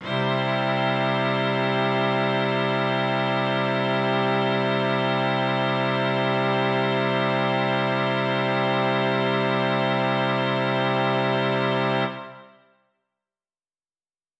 SO_KTron-Cello-Cmaj.wav